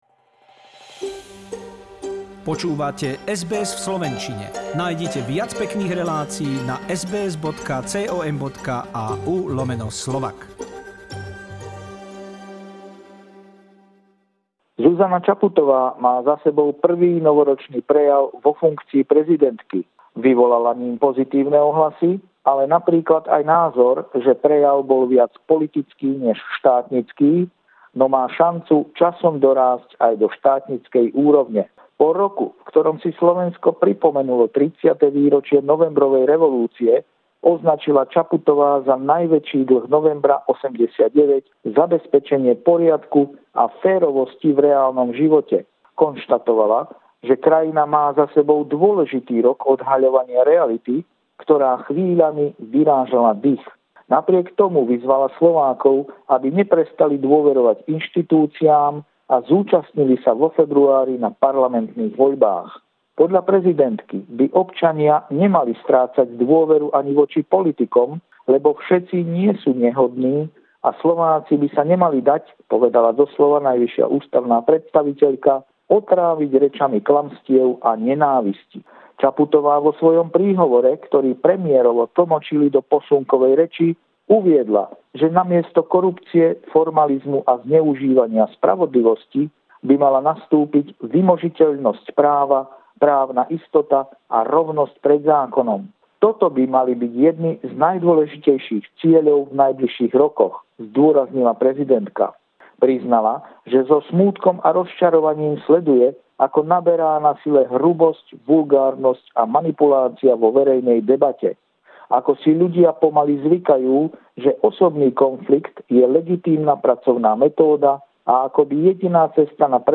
Regular stringer report